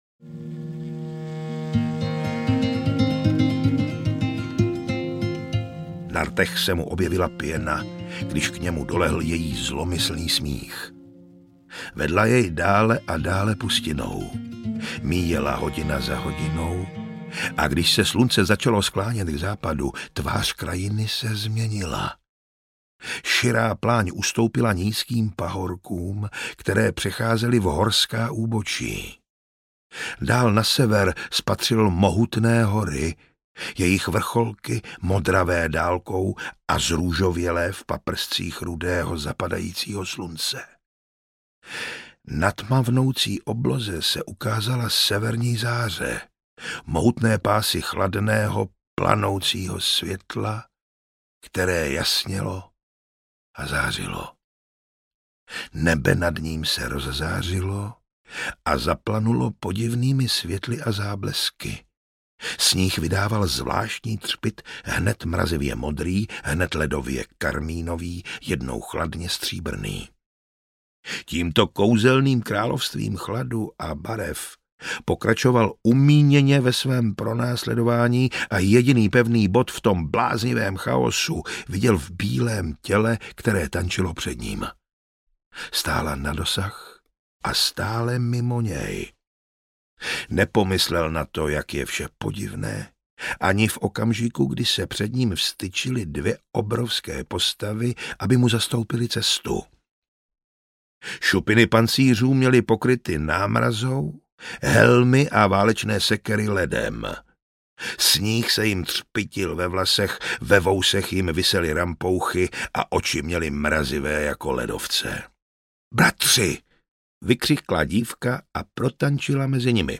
Conan z Cimmerie audiokniha
Ukázka z knihy